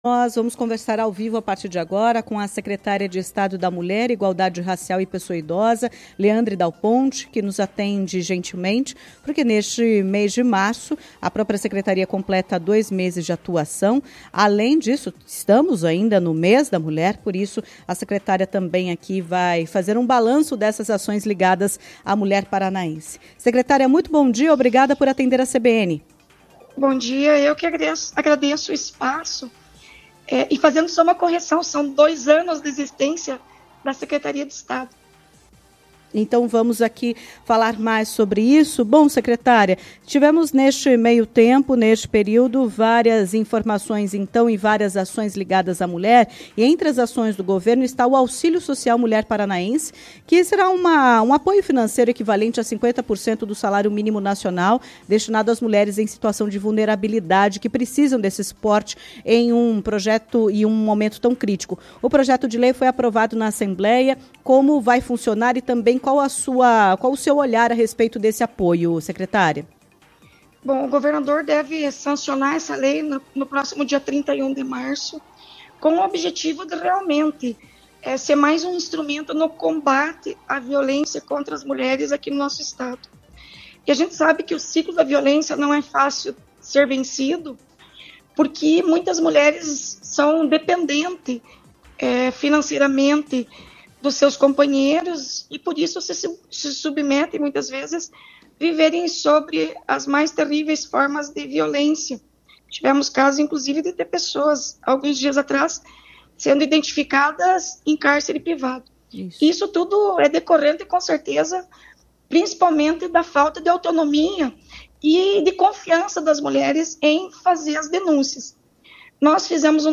Em entrevista à CBN Curitiba, Leandre ainda comentou sobre as novidades no atendimento à mulher vítima de violência, incluindo o Programa Recomeço e o Auxílio Social Mulher Paranaense.